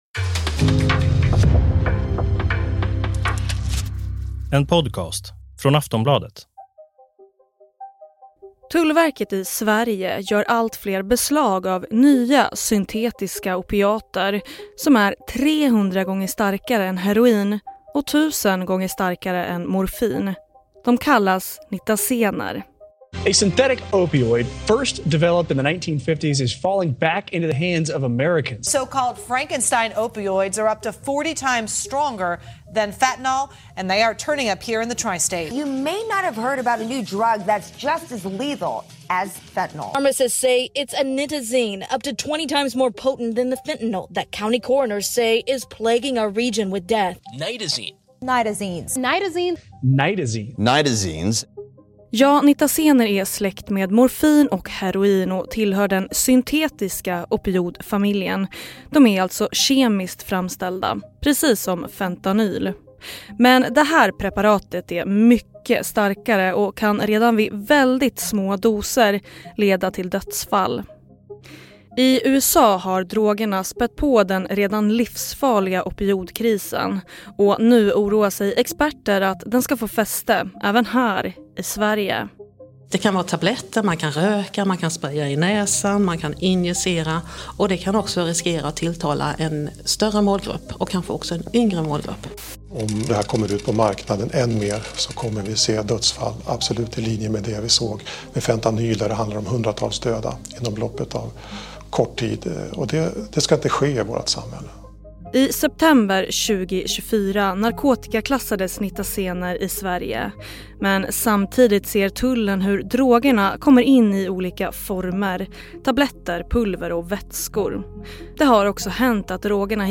Klipp i avsnittet: SVT Uppsala, P4 Halland, Youtubekanalen Hippocampus.